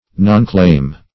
Search Result for " nonclaim" : The Collaborative International Dictionary of English v.0.48: Nonclaim \Non"claim`\, n. A failure to make claim within the time limited by law; omission of claim.